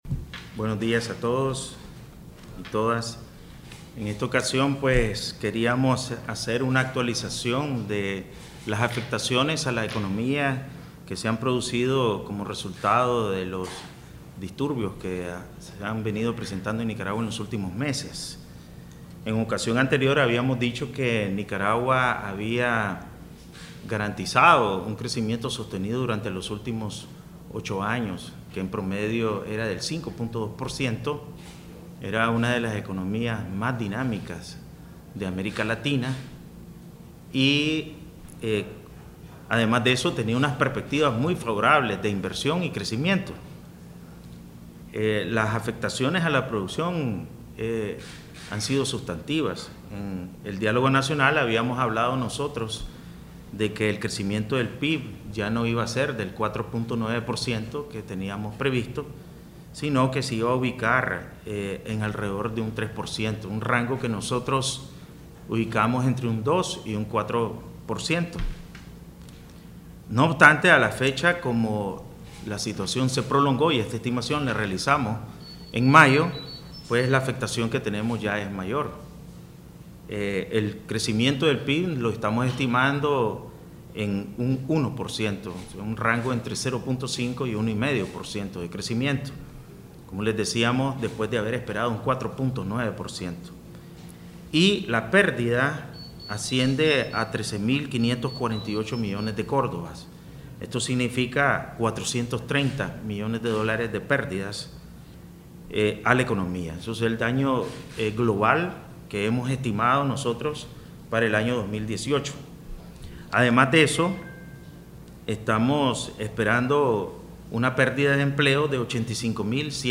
Palabras del Presidente del BCN, Cro. Ovidio Reyes R.